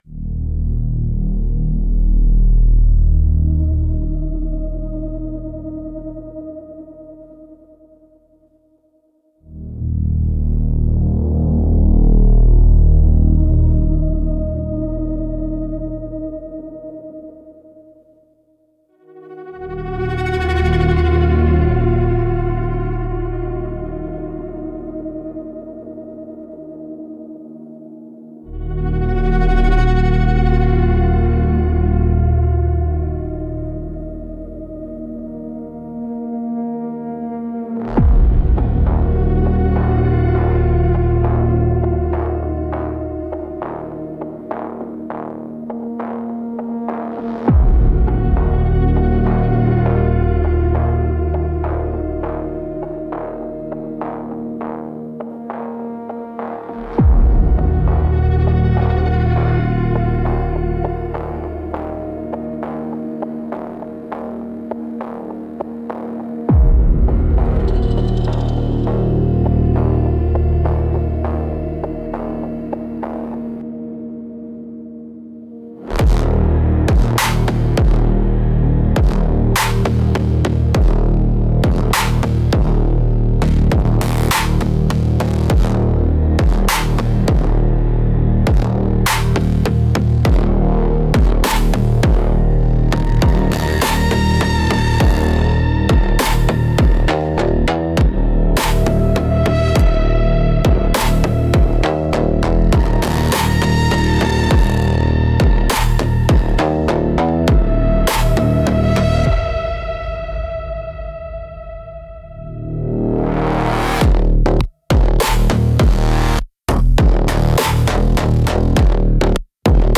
Instrumental - The Grinding Tempo of Decay 5.13